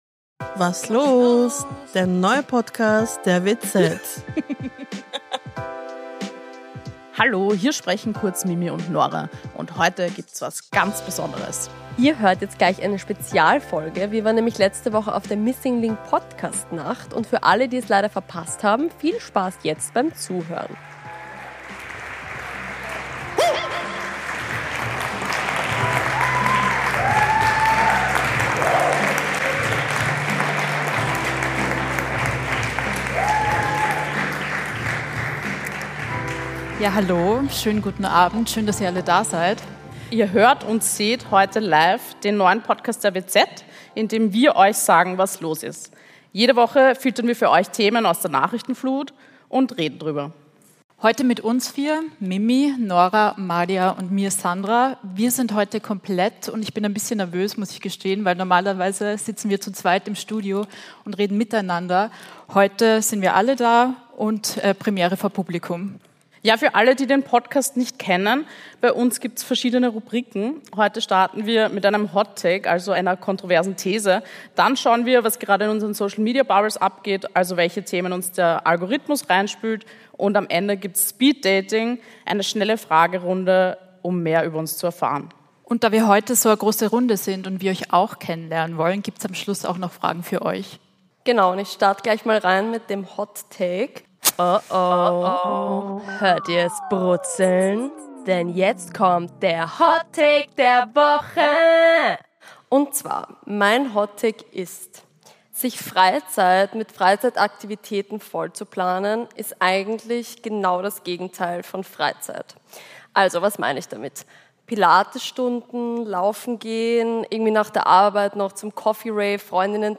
Beschreibung vor 1 Monat Der Was los?-Podcast war letzte Woche erstmals live: Und zwar bei der Missing Link-Podcastnacht! Die vier Hosts haben über das Gefühl, dass Freizeit immer mehr wie ein durchgetaktetes Projekt wirkt – zwischen Pilates, Coffee Rave und Leistungsdruck, gesprochen.
Und: Auch das Publikum wurde befragt: Was sagt ihr?